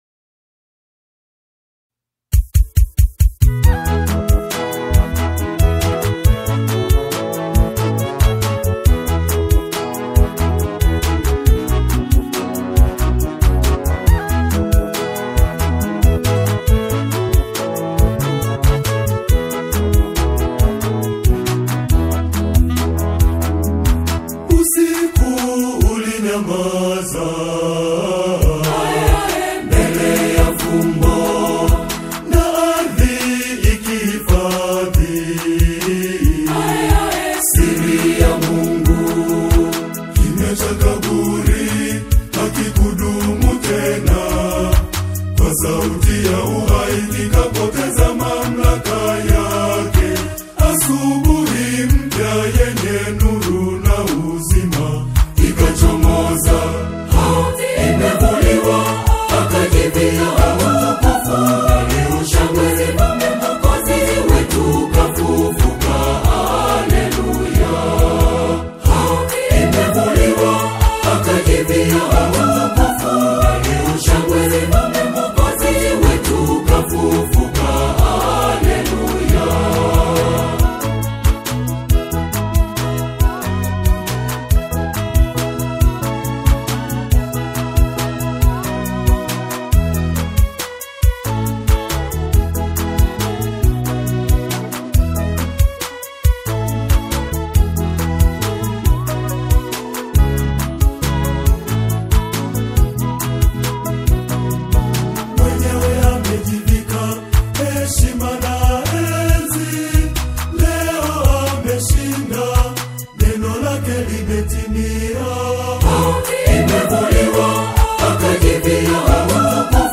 a powerful and triumphant anthem
Catholic choirs